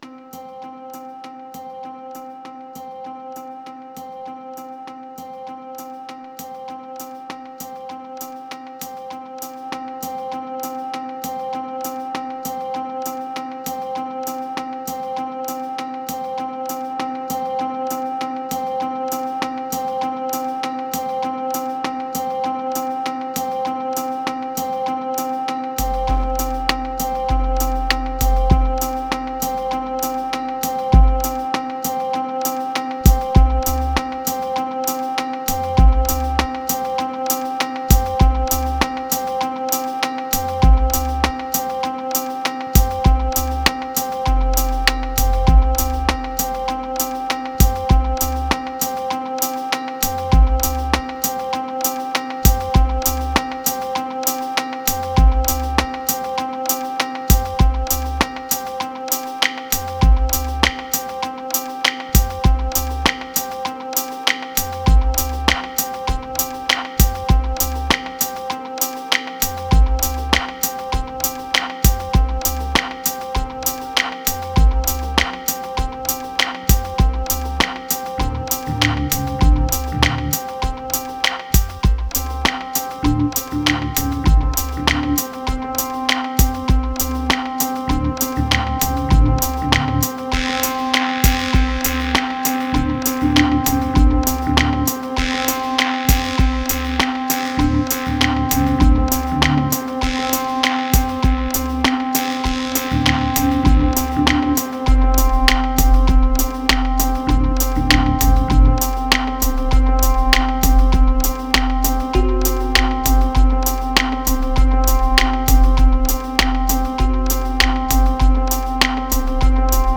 Mantra Epic Hope Morning Prayer Unity